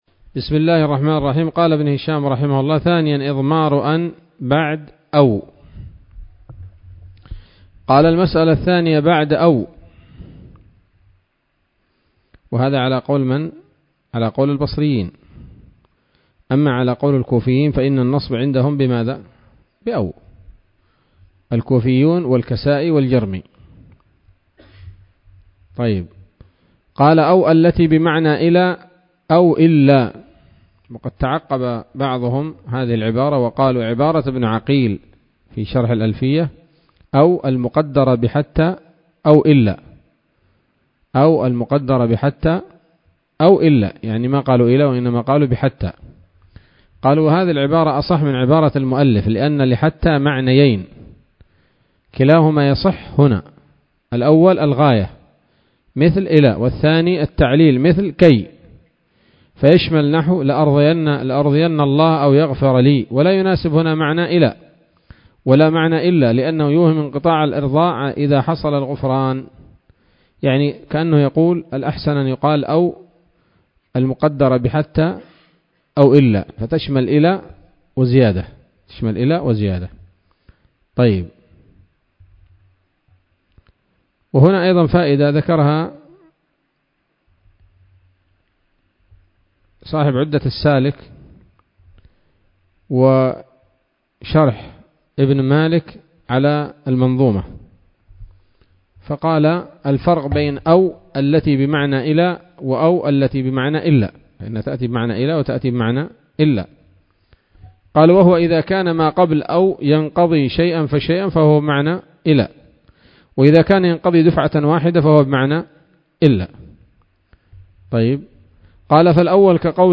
الدرس الثاني والثلاثون من شرح قطر الندى وبل الصدى [1444هـ]